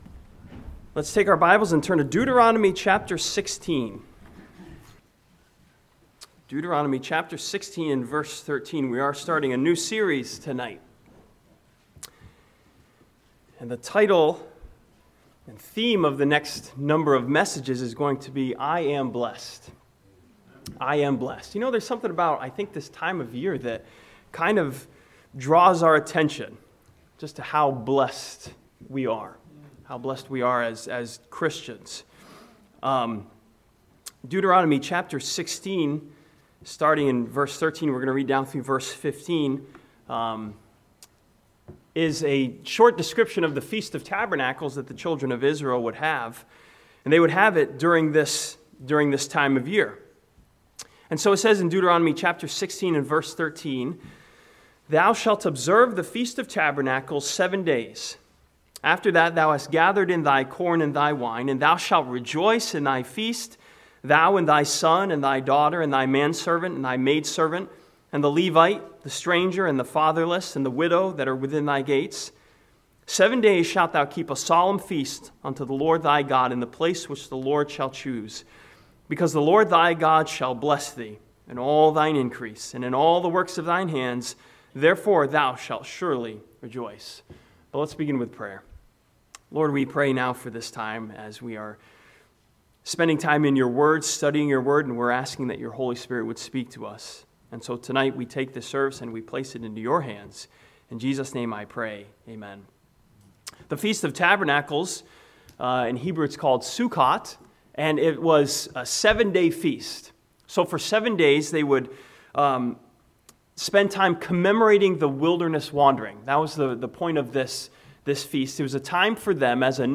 This sermon from Deuteronomy 16 starts a new series titled "I Am Blessed" that encourages us to rejoice in the blessings of God.